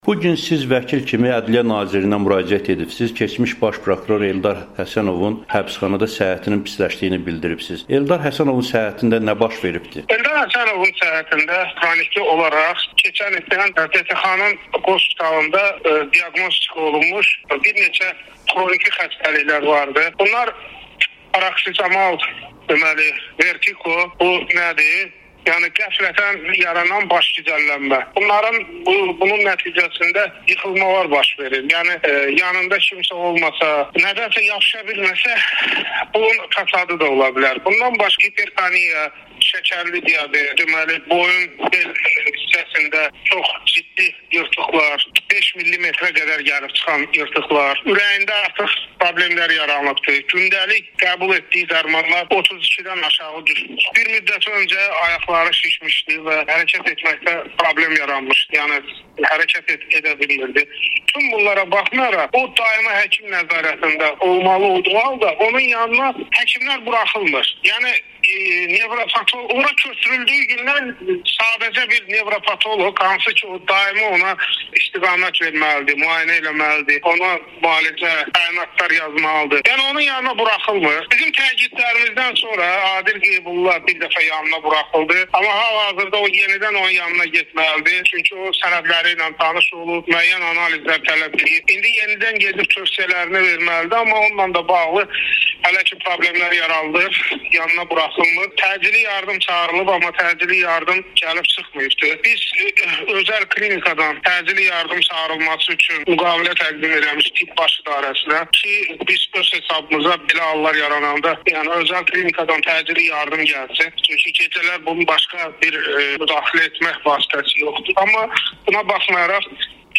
Amerikanın Səsinə müsahibəsində